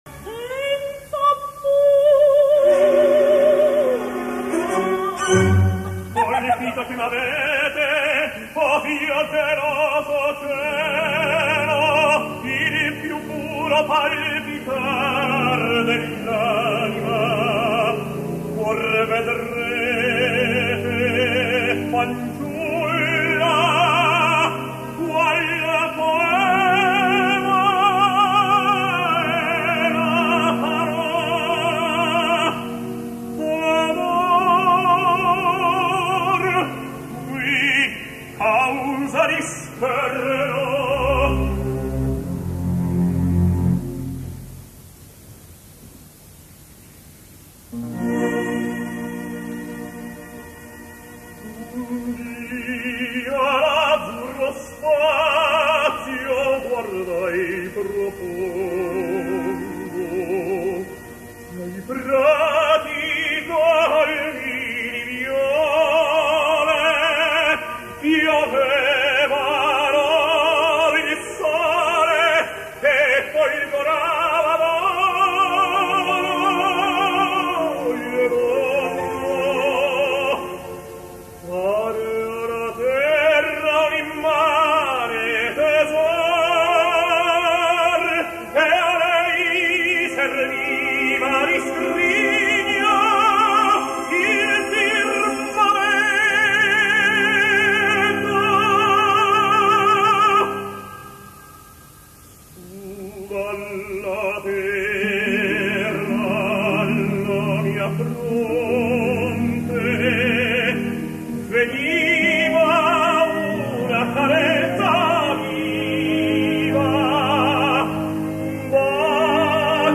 Ah! i perquè no dir-ho!, perquè les confrontacions de tenors sempre són les més disputades i porten controvèrsia, comentaris i vistes, quelcom que sempre revitalitza el blog.